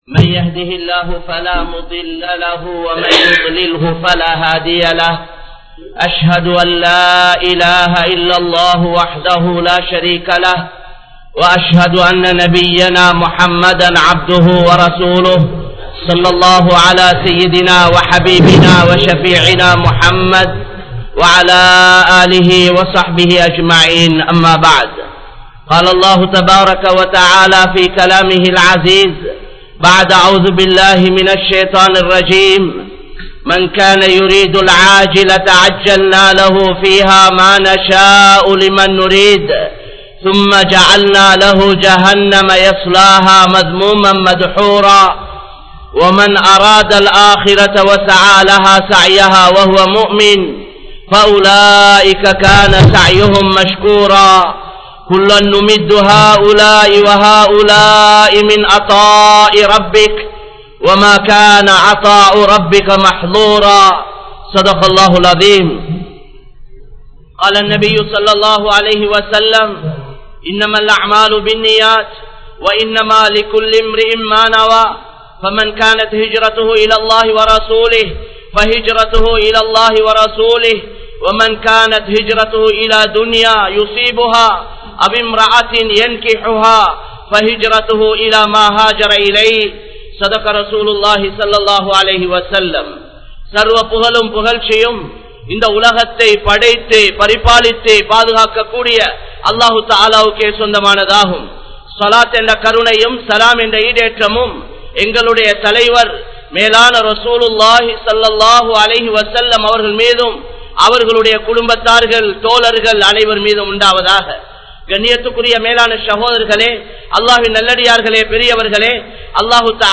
Iv Ulahil Ethuvum Nirantharam Illai (இவ்வுலகில் எதுவும் நிரந்தரமில்லை) | Audio Bayans | All Ceylon Muslim Youth Community | Addalaichenai
Kollupitty Jumua Masjith